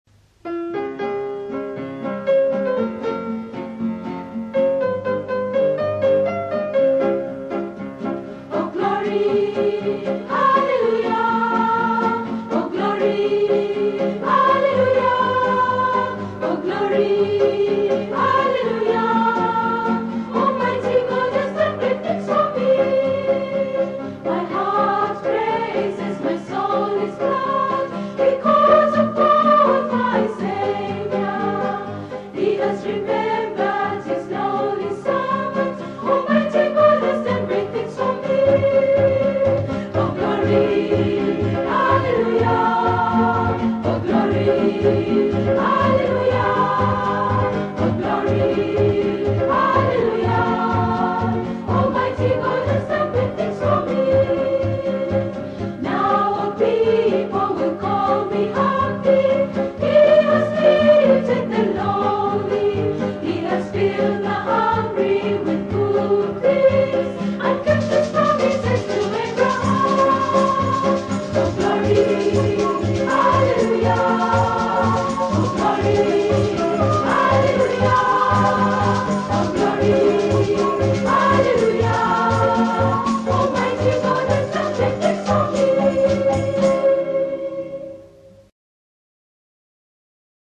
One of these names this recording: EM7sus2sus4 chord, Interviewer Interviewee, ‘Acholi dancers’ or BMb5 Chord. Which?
‘Acholi dancers’